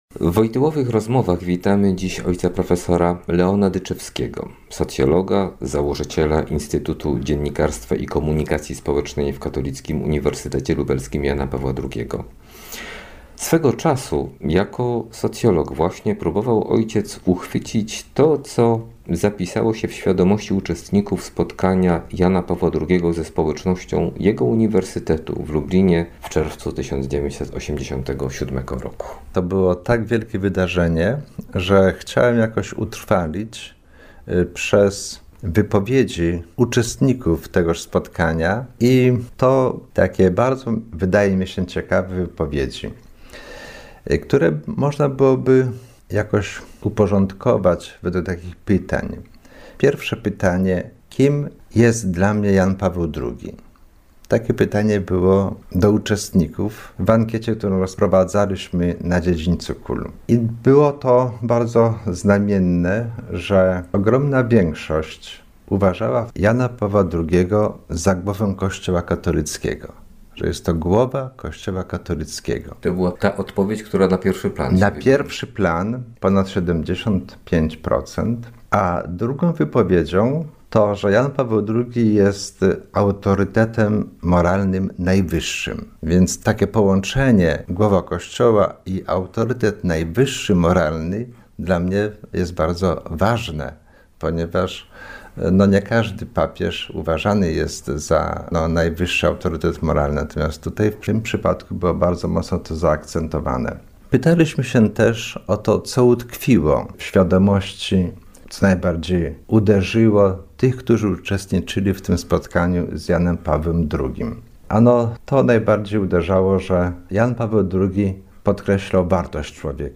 Cykl rozmów z profesorami KUL o Karolu Wojtyle - Janie Pawle II